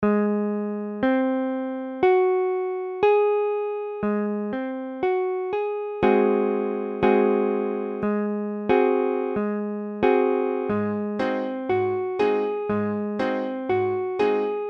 Ab7 : accord de La b�mol septi�me Mesure : 4/4
Tempo : 1/4=60